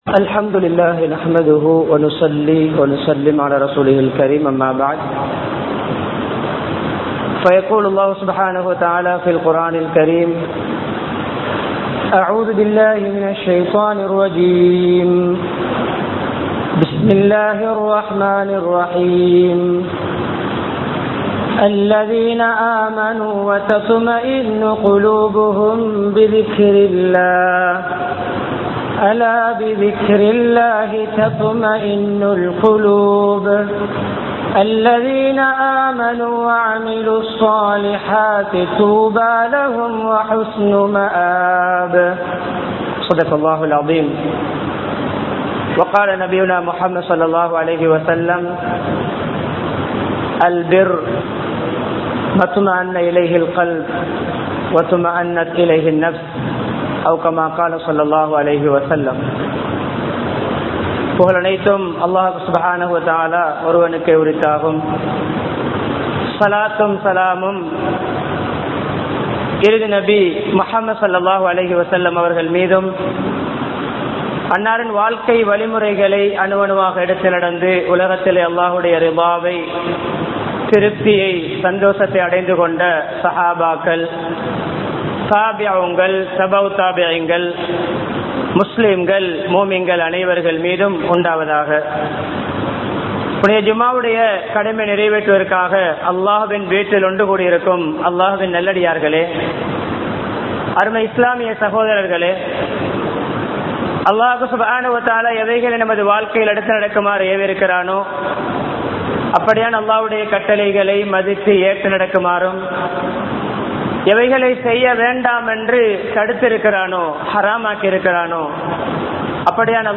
மன நிம்மதிக்கான வழிகள் | Audio Bayans | All Ceylon Muslim Youth Community | Addalaichenai
Town Jumuah Masjith